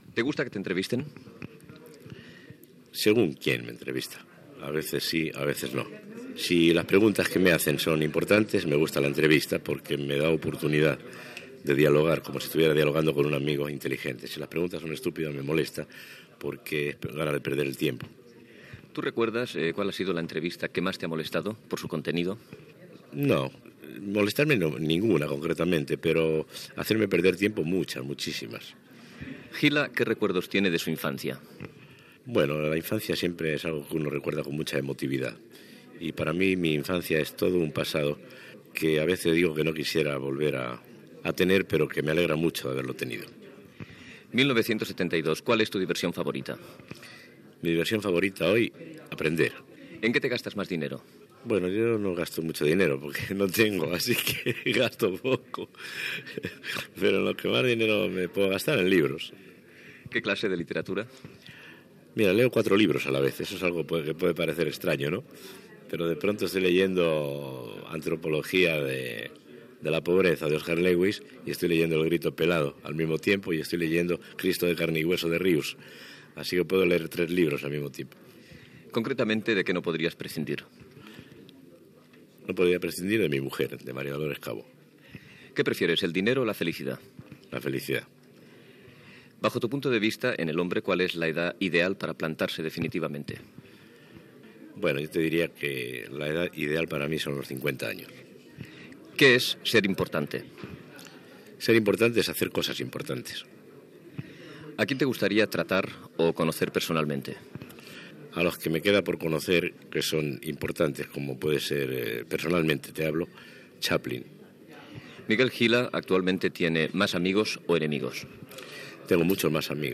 Entrevista a l'humorista Miguel Gila, sobre les seves opinions sobre les entrevistes, la religió, l'ecologia, preferències vitals, etc.
Entreteniment